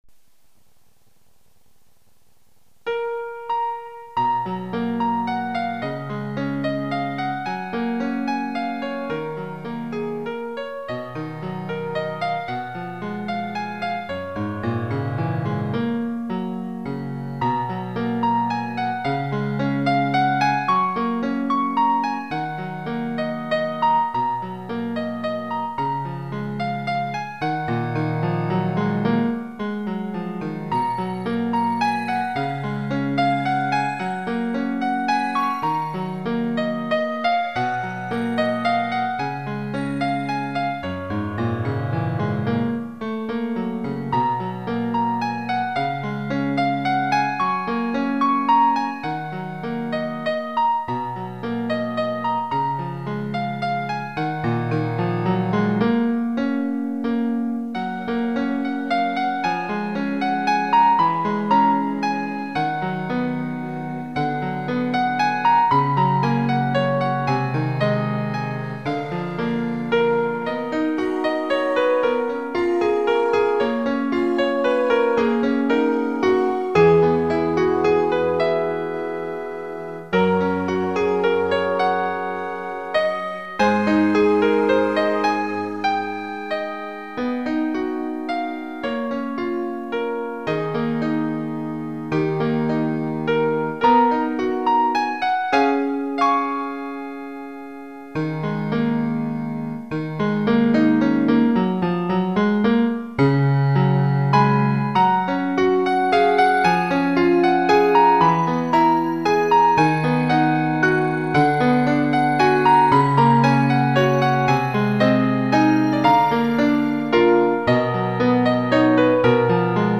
シンプルで物悲しめなピアノメインの曲